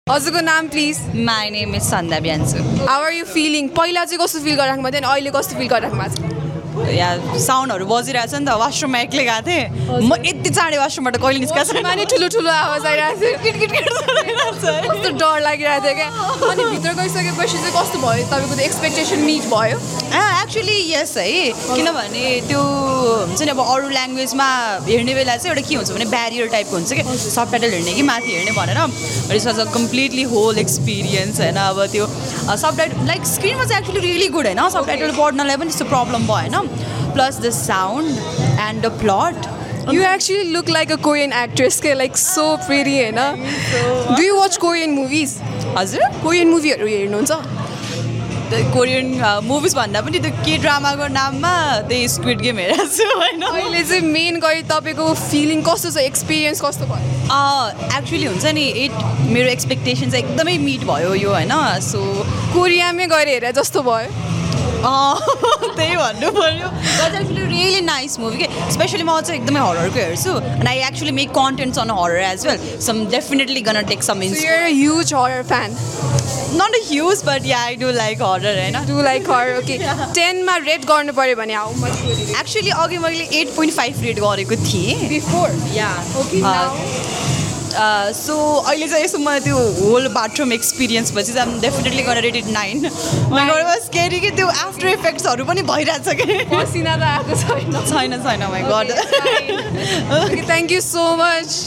from Today in Nepals Cinema Hall